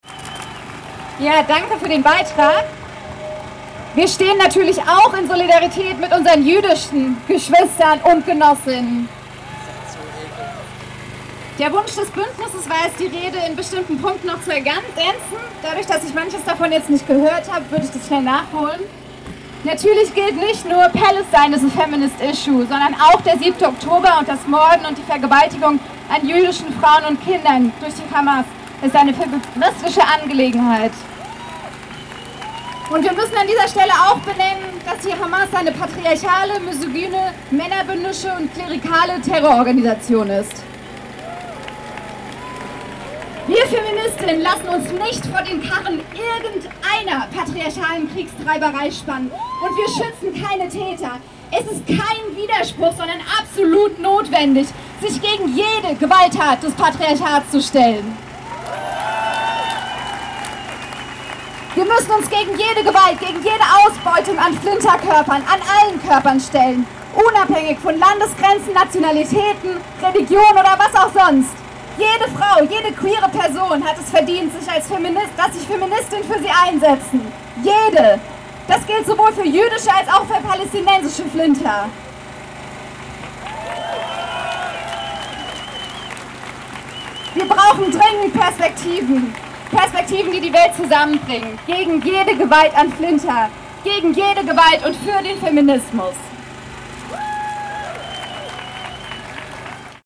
In den frühen Abenstunden, des 8. März 2024, demonstrierten in Freiburg mehrere Tausend Menschen für Geschlechtergerechtigkeit und gegen Sexismus und Patriarchat.